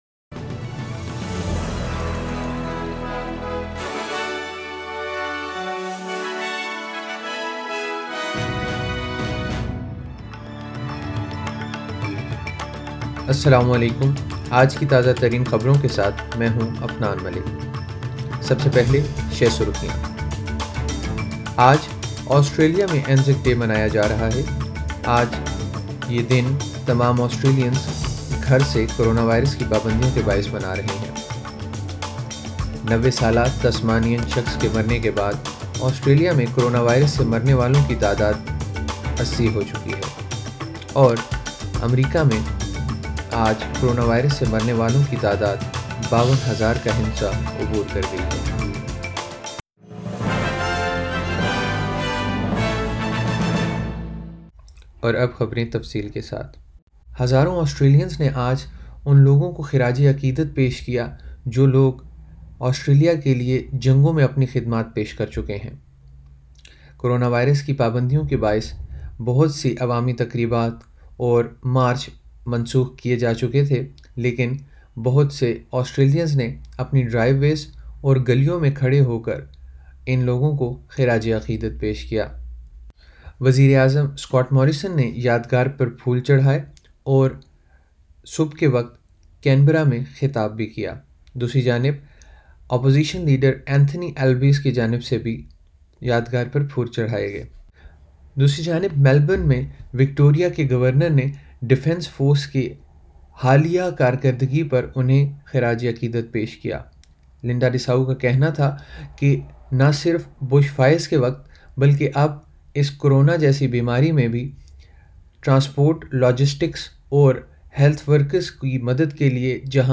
SBS Urdu News 25 Apr 2020